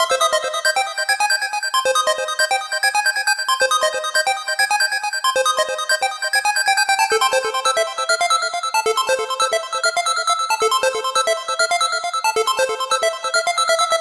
Lead 137-BPM 1-C.wav